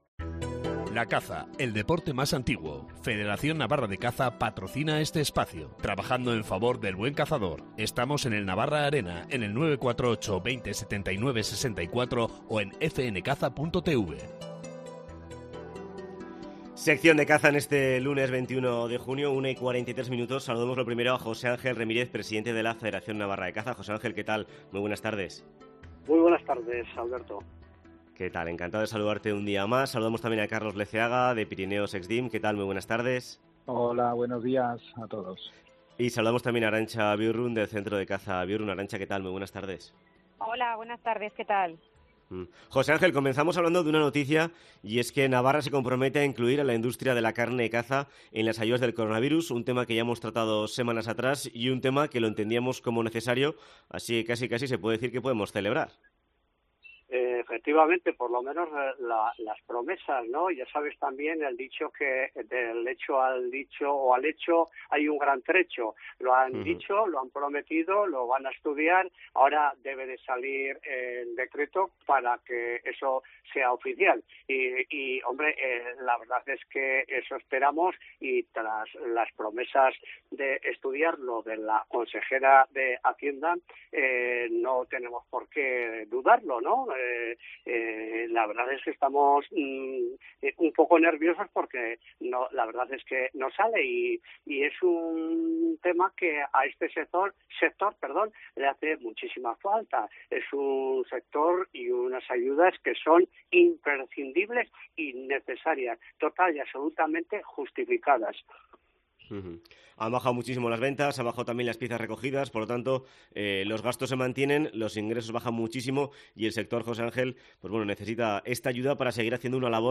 Sección de caza en COPE Navarra del 21 de junio